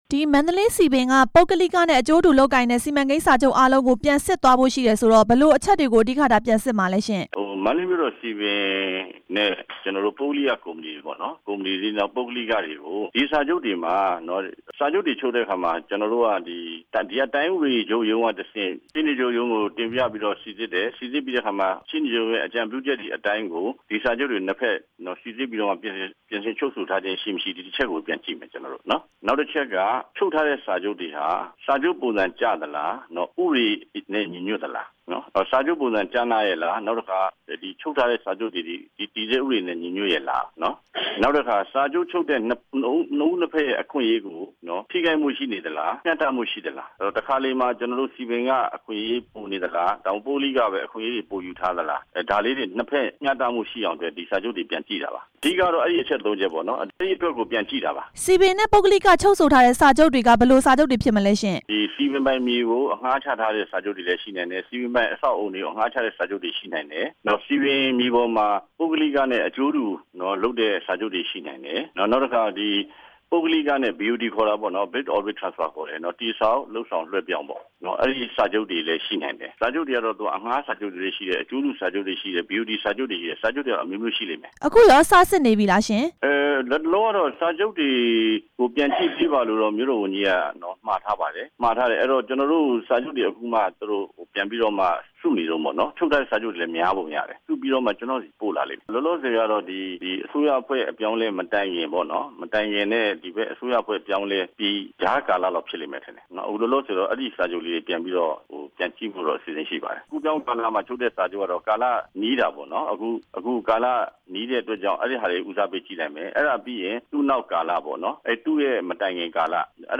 မန္တလေး စည်ပင်ကော်မတီအတွင်းရေးမှူး ဦးအောင်ကျော်ထွန်းနဲ့ မေးမြန်းချက်